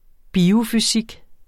Udtale [ ˈbiːo- ]